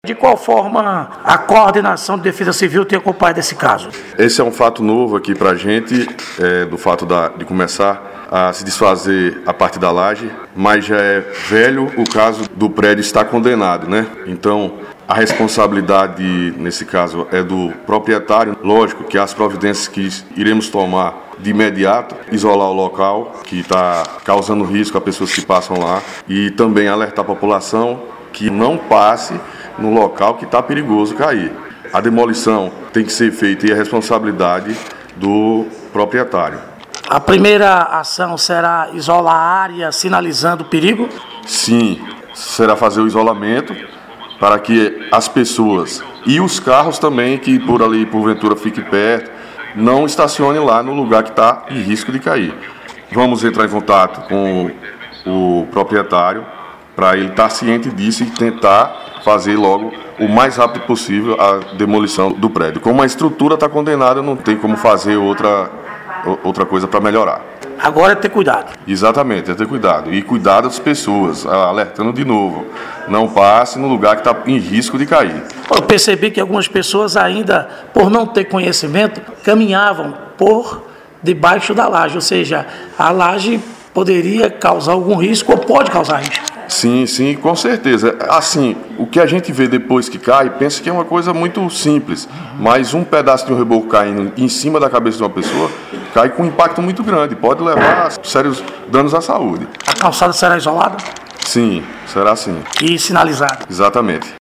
Um prédio que está desativado e condenado poderá desabar ao lado da Praça Helder Menezes, centro de Campo Formoso (BA). A informação foi confirmada pelo coordenador da Defesa Civil do município, Flávio Palha, em entrevista ao 98 Notícias desta segunda-feira (01).